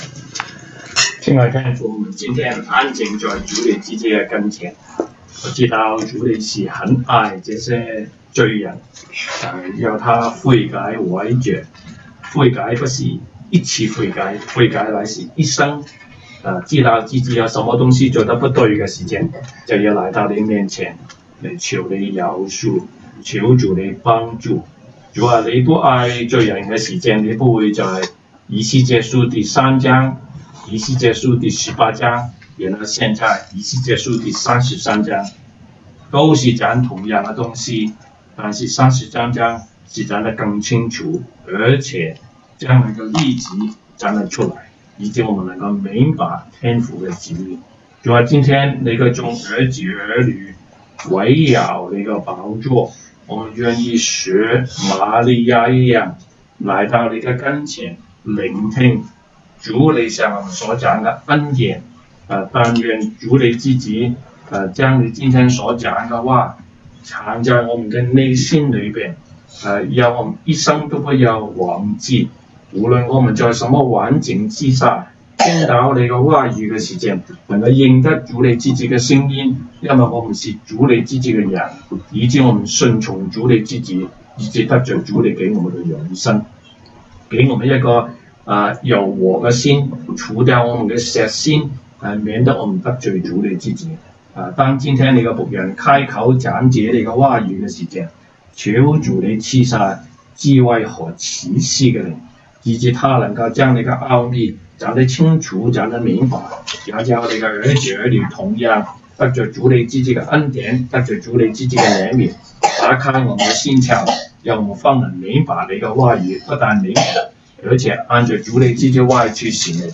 Monday Bible Study